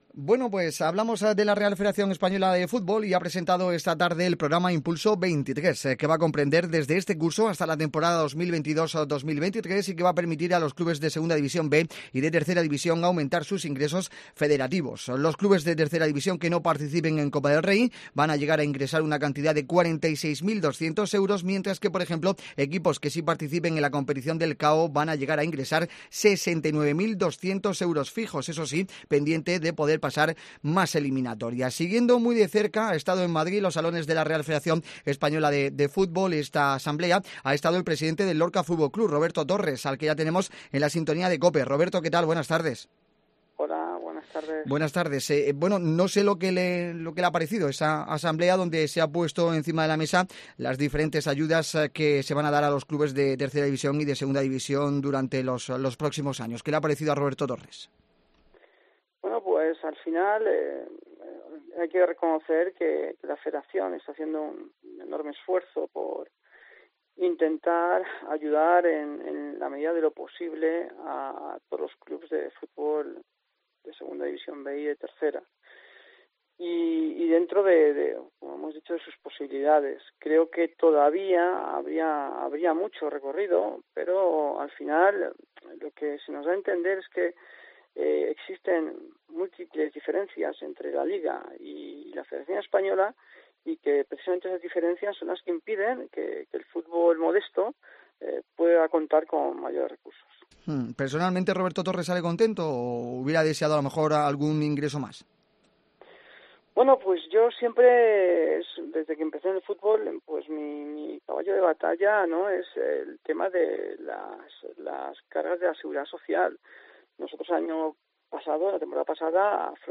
ENTREVISTA DXT COPE